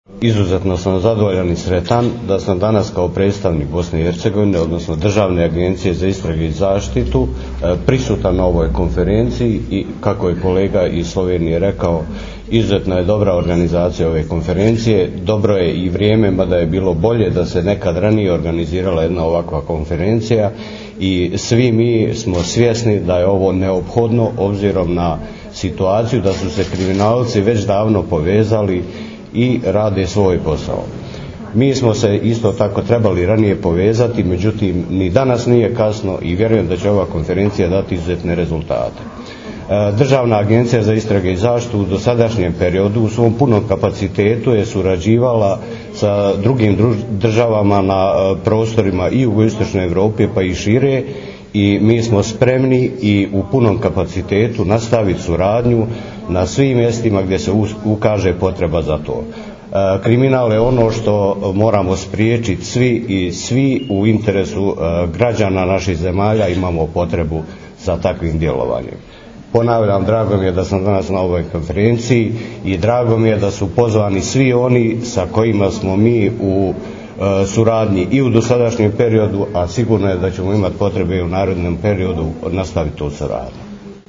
Zvočni posnetek izjave Marka Dominkoviča, namestnika direktorja SIPE (državne agencije za preiskave in zaščito) v BiH (mp3)